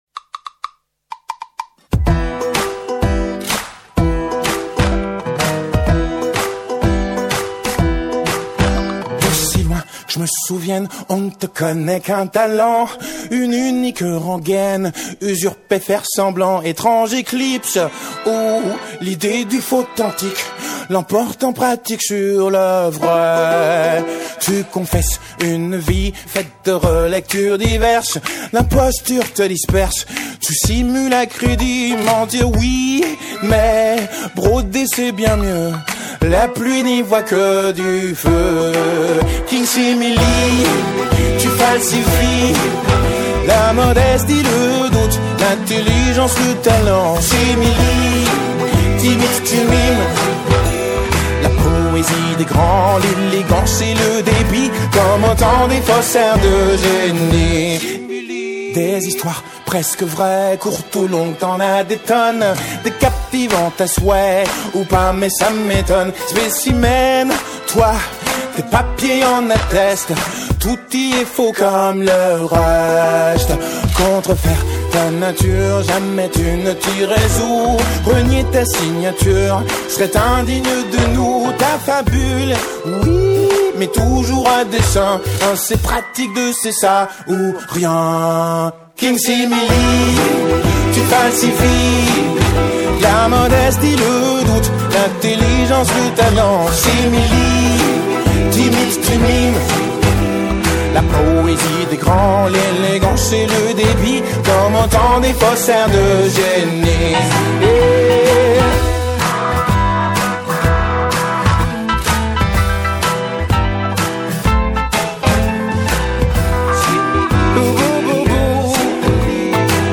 Invité du Mag hier soir, le chanteur Tété nous présente son dernier album et la nouvelle tournée qui s’annonce et qui passera par la Citrouille à Saint-Brieuc le vendredi 08 mars à 21h et c’est déjà complet ( on vient tout juste de l’apprendre)